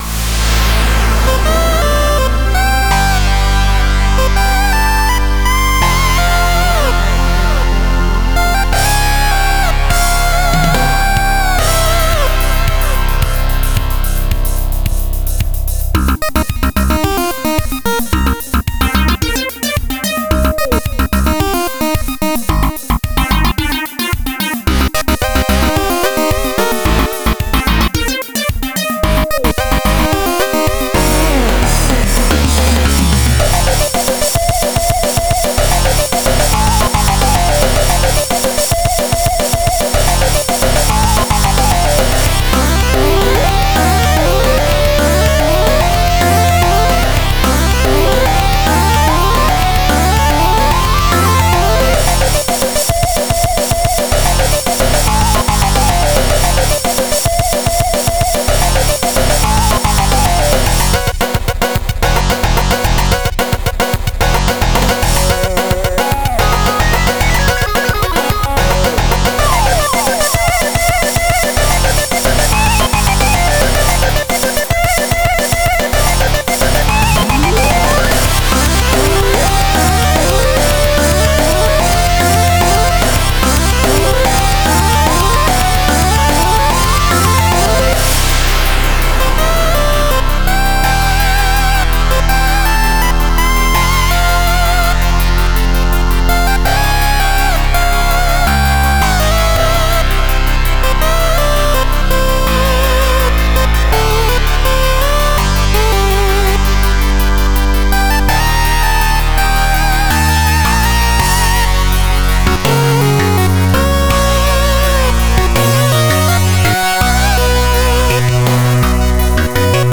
Love the mixture of smooth and chip sound here.